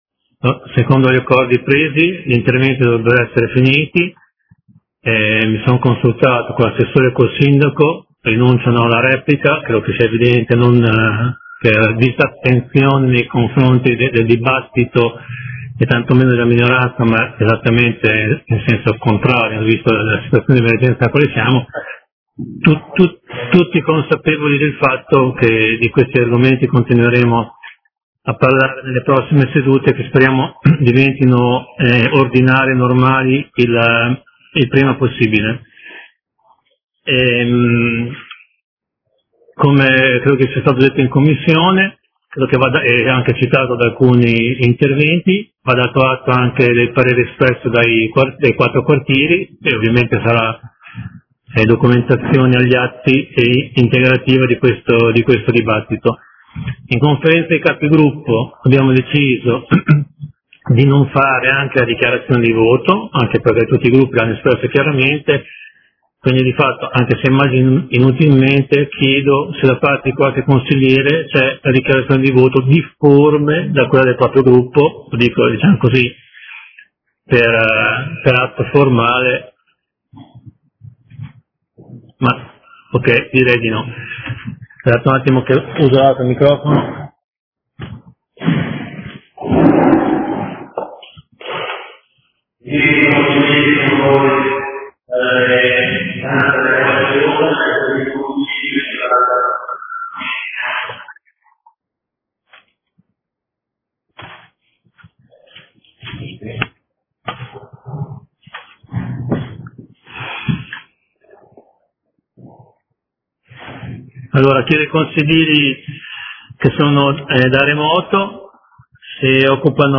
Chiusura lavori con intervento finale del consigliere Carpentieri